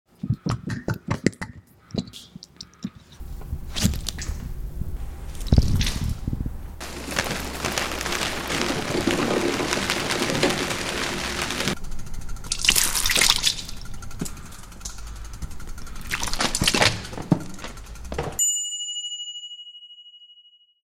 ASMR recording of “Purl” 🧿 sound effects free download
ASMR recording of “Purl” 🧿 capturing the sound of the paint splashes and the spinning canvas.